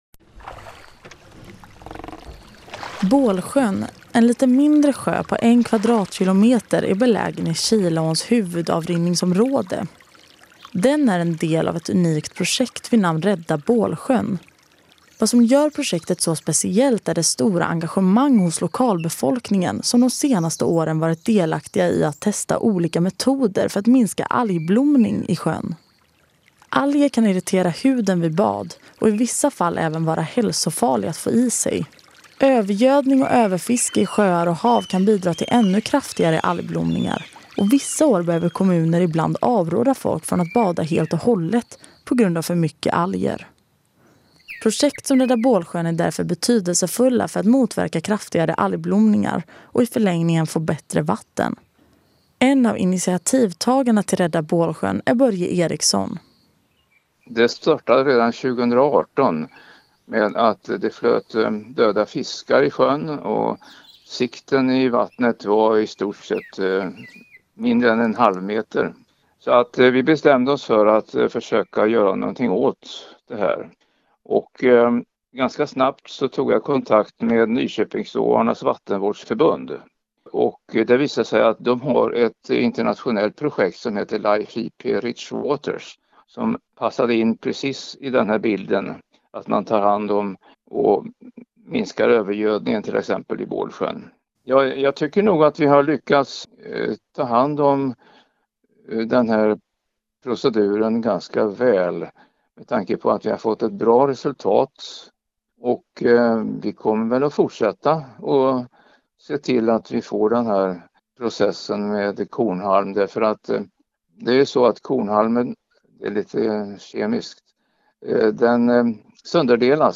Ljudvandring i Kiladalen: